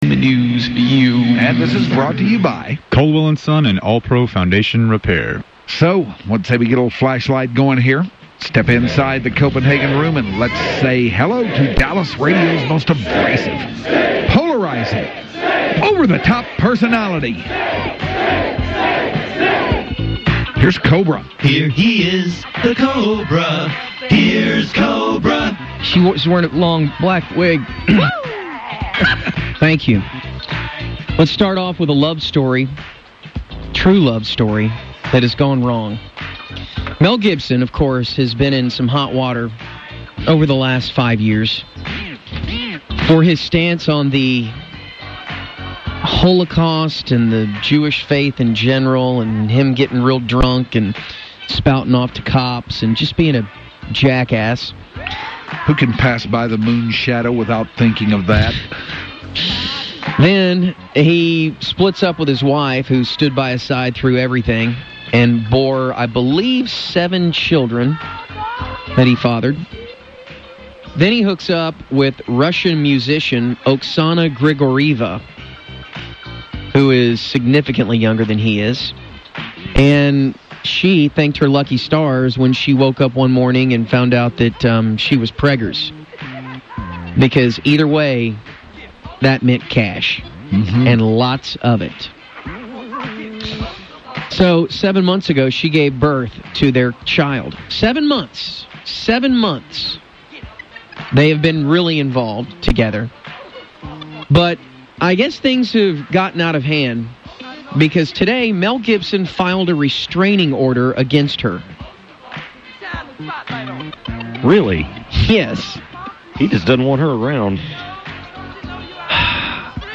So drunk he got the giggles and ended the segment with what they say was a “S-Bomb”.